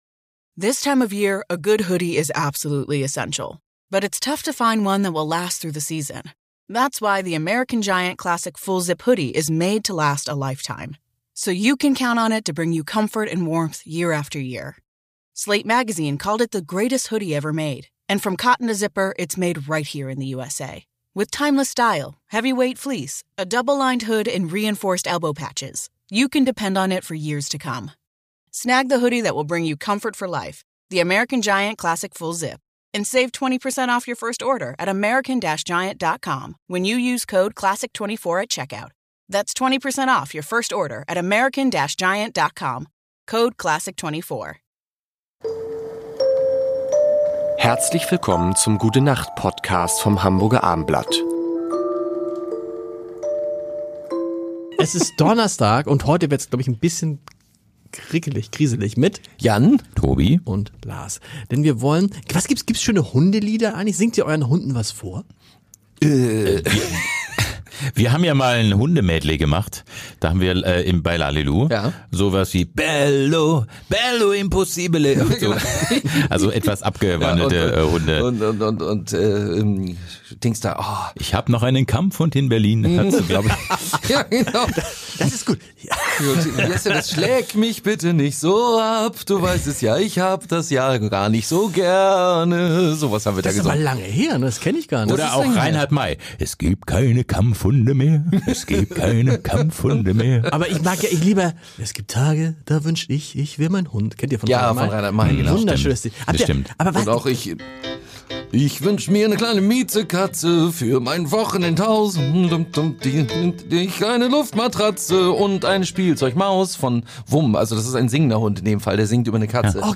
und natürlich sehr musikalische fünf Minuten. Es wird gesungen,
gelacht und philosophiert, und Stargäste sind auch dabei.